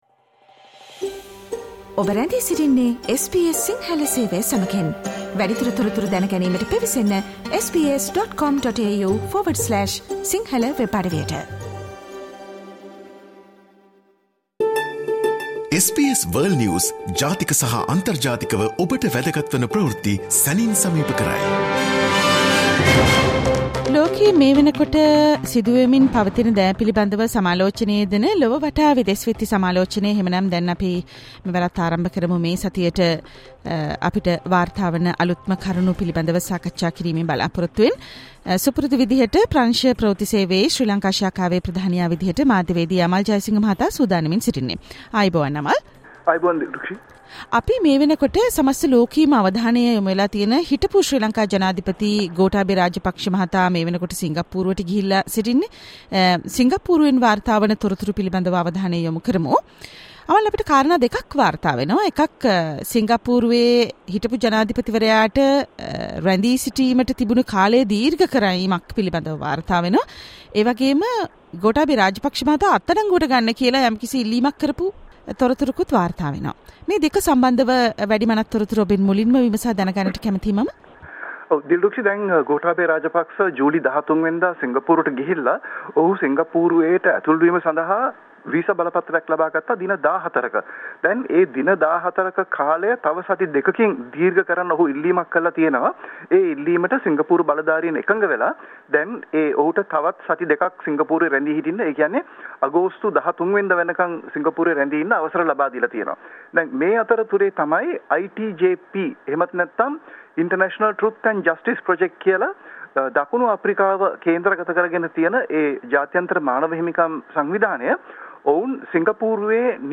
and the world news critic World's prominent news highlights in a few minutes - listen to SBS Si Sinhala Radio's weekly world News wrap on Friday Share